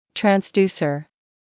[trænz’djuːsə], [trɑːn-][трэнз’дйуːсэ], [траːн-]трансдьюсер; передатчик, преобразователь